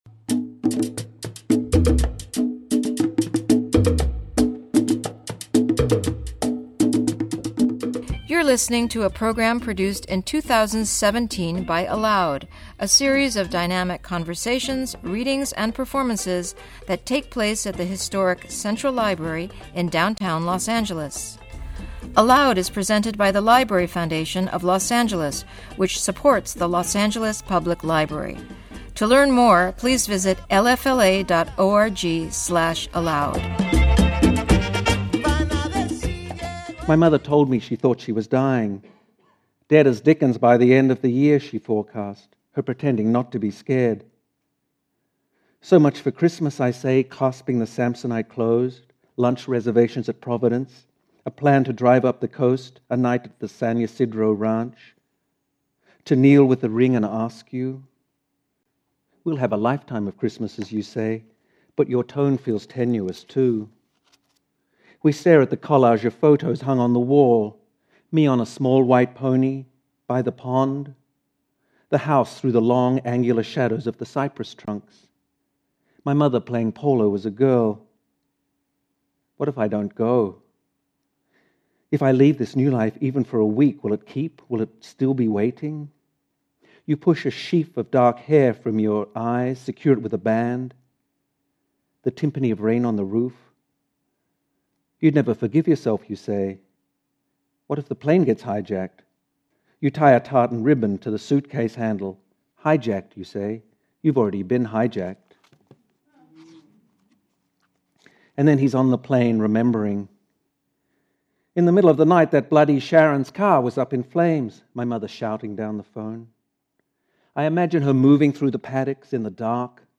In conversation with author Jane Smiley